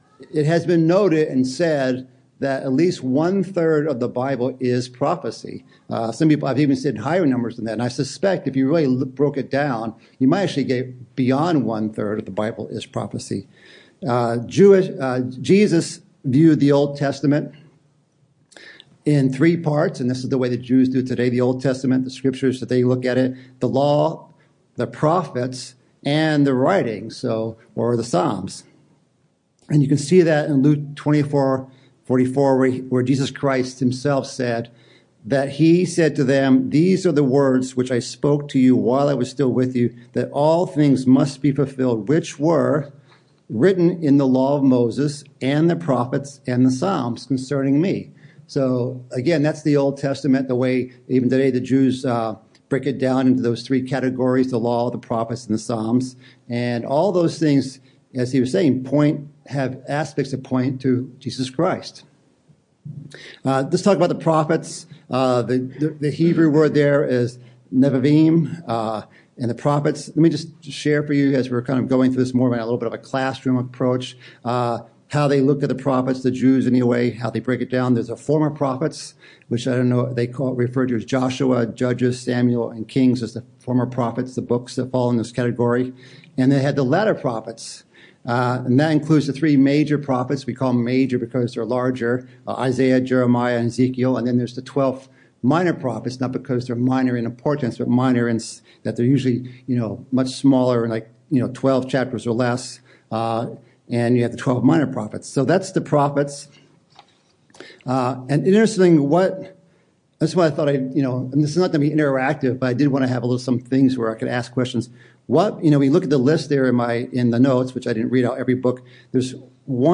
Congregational Study: Prophecy Series #1 - Introduction to Prophecy
On the first Sabbath of each month, the Beloit, Chicago, and Northwest congregations hold a joint study session.